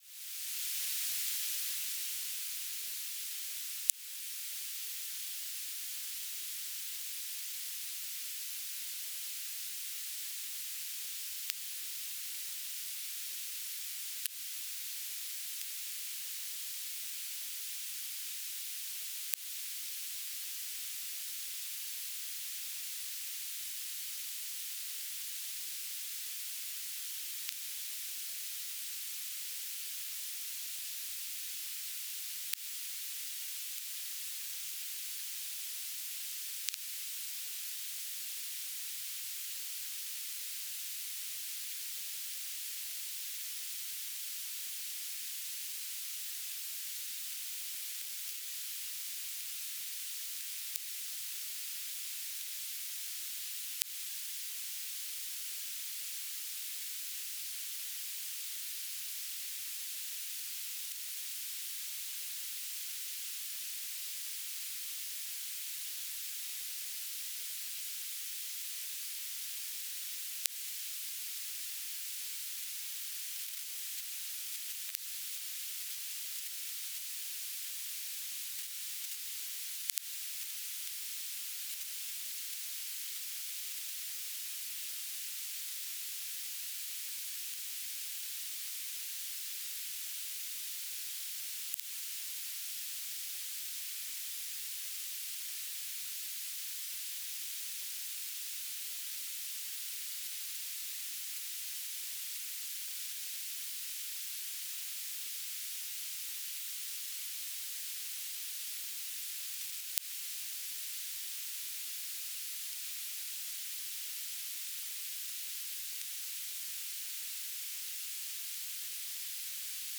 "transmitter_description": "Mode S - BPSK 2Mbps TLM",